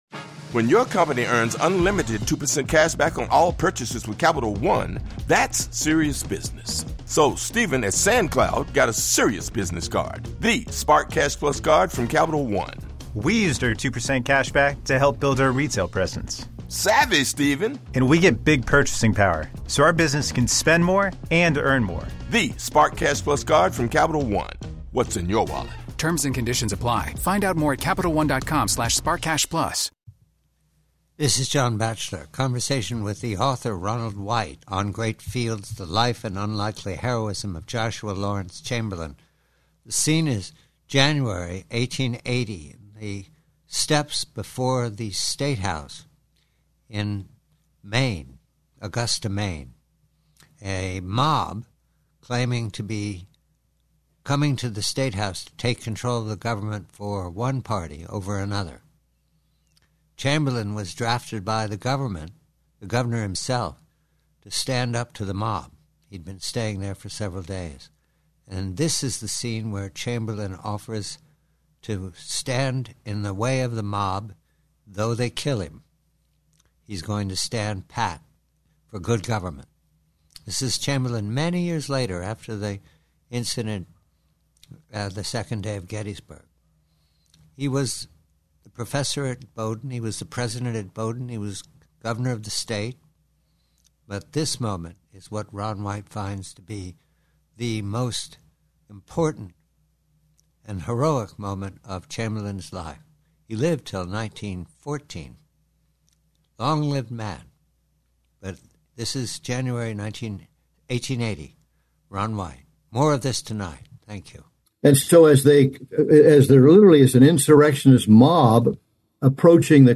PREVIEW: MAINE: 1880: Conversation with Ronald White, author, ON GREAT FIELDS, re the life of the hero of Little Round Top, Joshua Chamberlain, re the stand Chamberlain made in 1880 to face down a mob at the Augusta Statehouse.